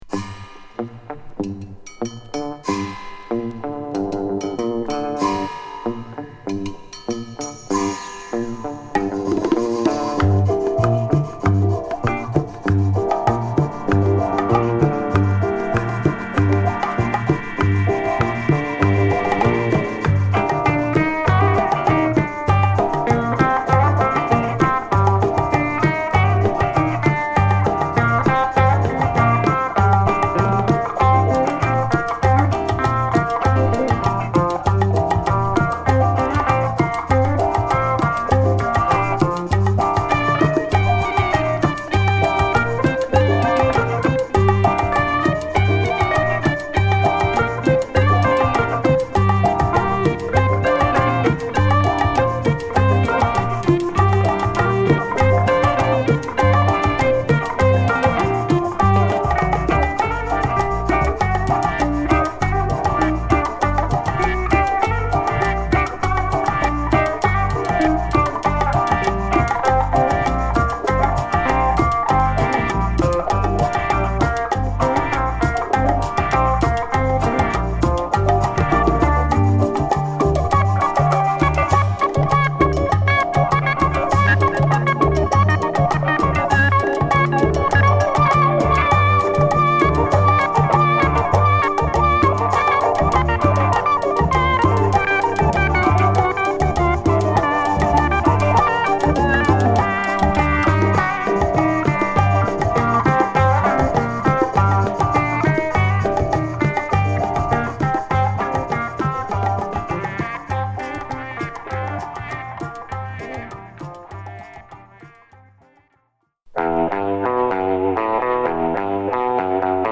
クンビアのリズムにサーフ調のギターや鍵盤等を絡め、ミクスチャーなサウンドに仕上げたオーガニックなモダンチーチャ！